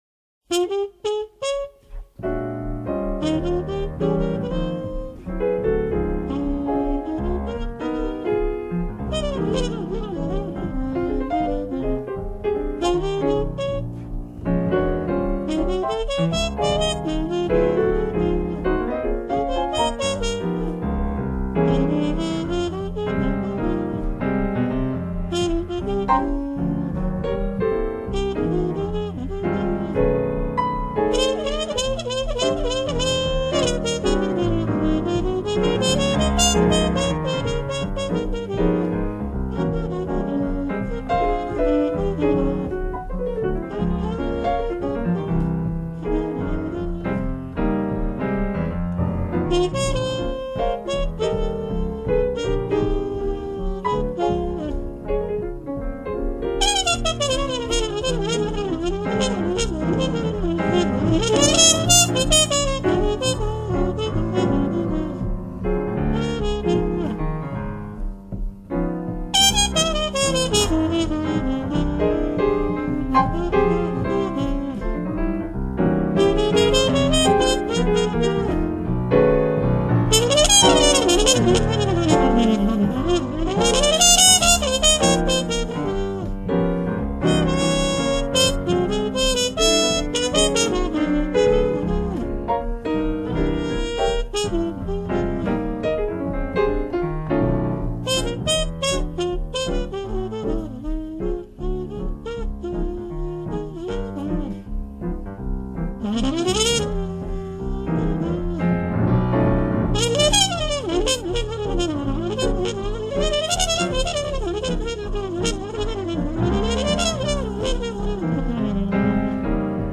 jazz standard